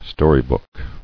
[sto·ry·book]